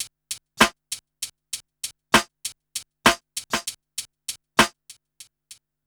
1TI98BEAT4-R.wav